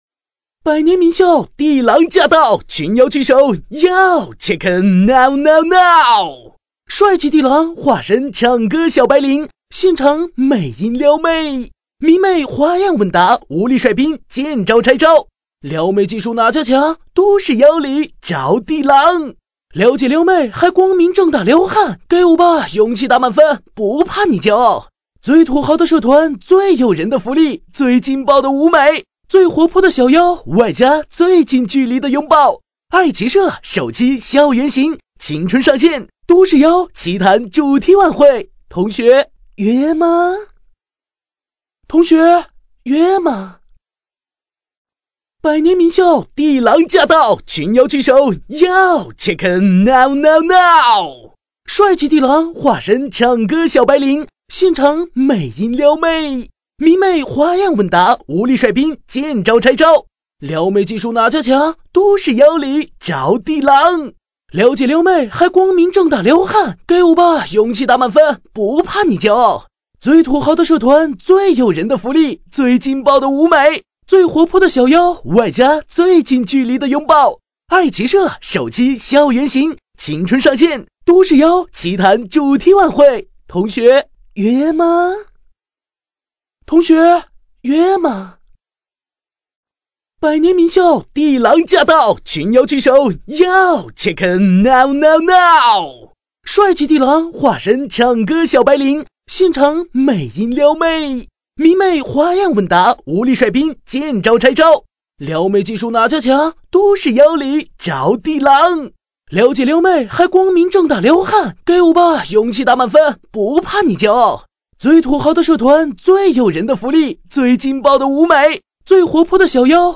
国语青年积极向上 、时尚活力 、素人 、脱口秀 、男飞碟说/MG 、100元/分钟男B073 国语 男声 飞碟说-MG动画 极限挑战（综艺风格） 积极向上|时尚活力|素人|脱口秀